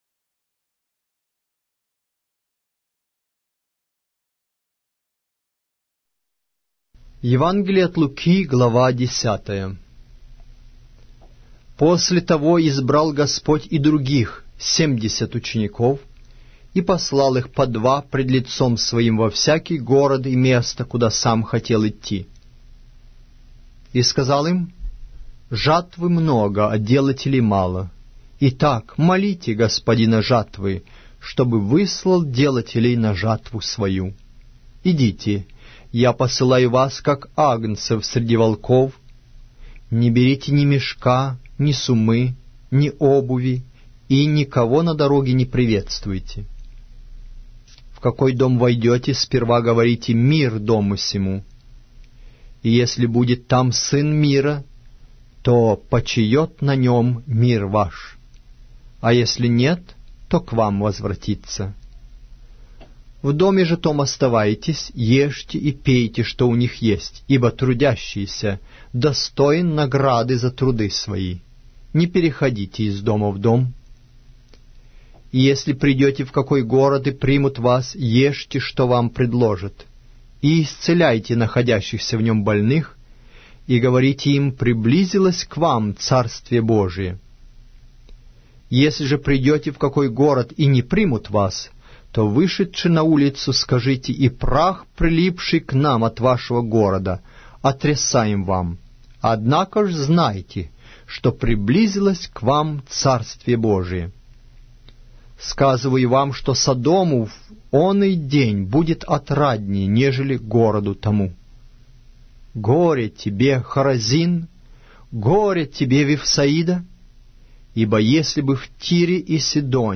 Аудиокнига: Евангелие от Луки